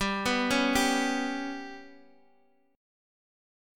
GMb5 Chord